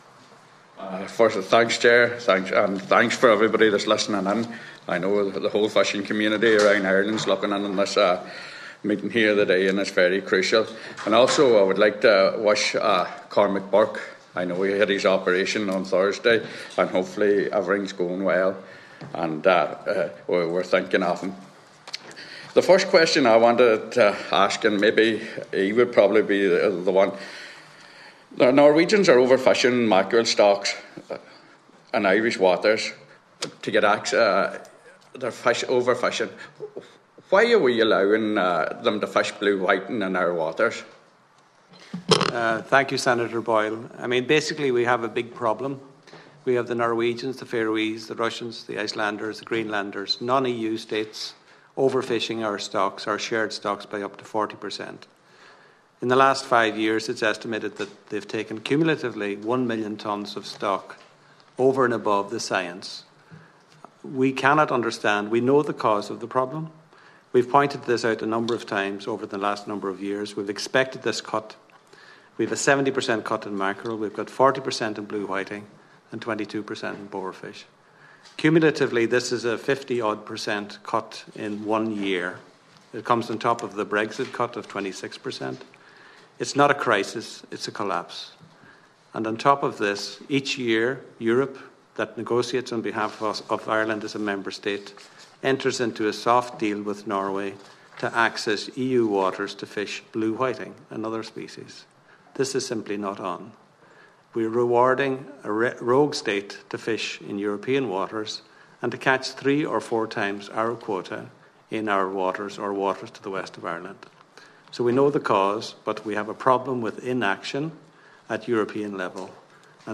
Todays Oireachtas Hearing on Fishing Quotas and Common Fisheries Policy has heard that in one week alone, Norwegian fishing trawlers landed 40,000 tonnes of Mackeral, which is quota for Ireland for the entire year.